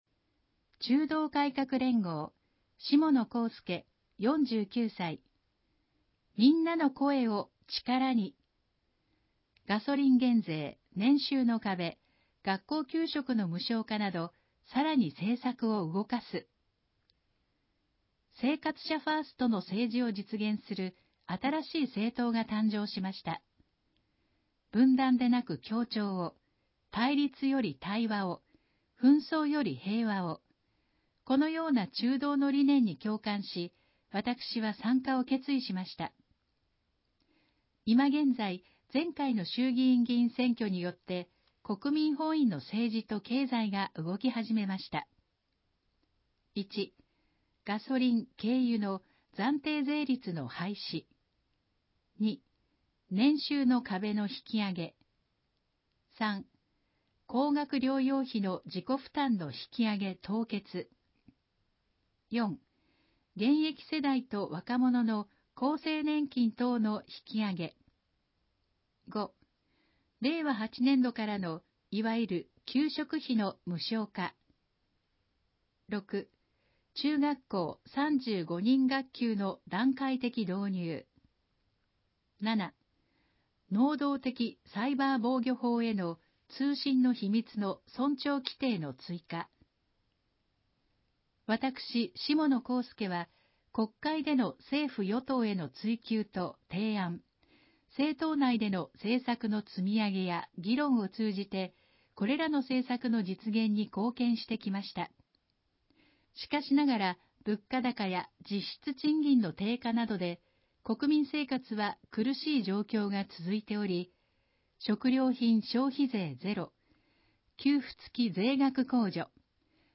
選挙公報の音声読み上げ対応データ（候補者提出）